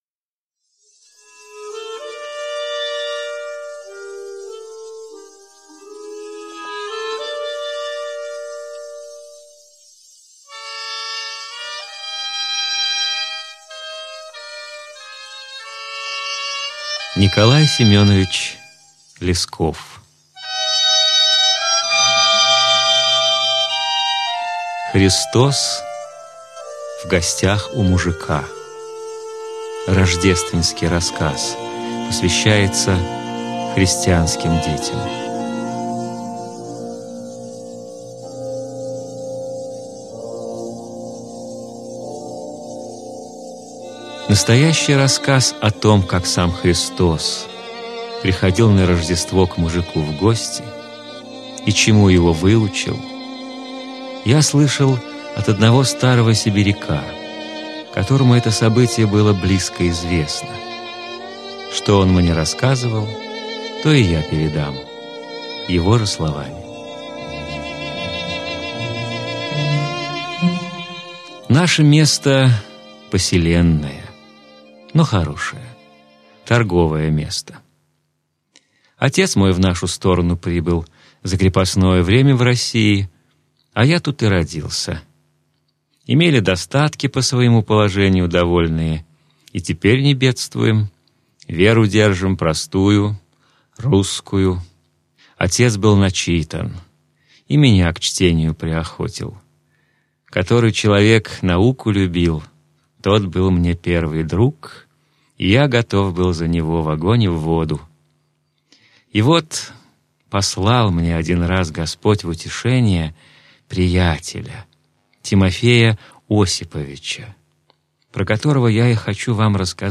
Христос в гостях у мужика - аудио рассказ Лескова Н.С. Рассказ про ссыльного Тимофея Осиповича.